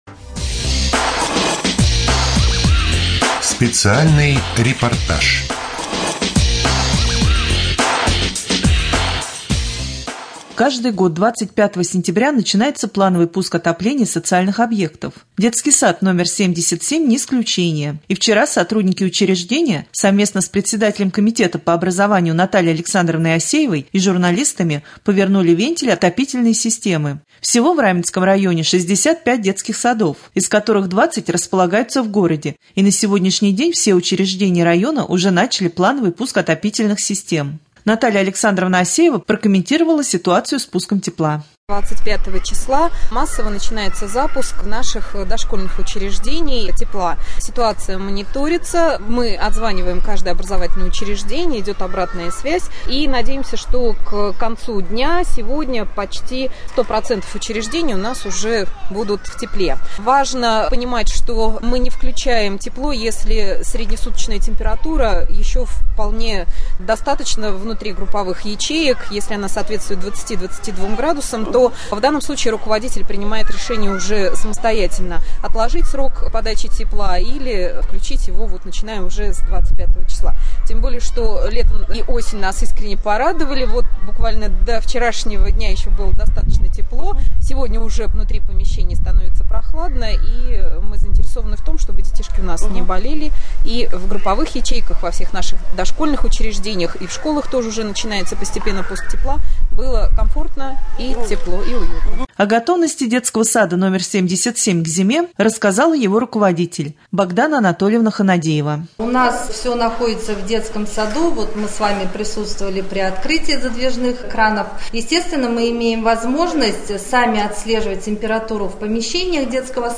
2.Рубрика «Специальный репортаж».